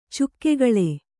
♪ cukkegaḷe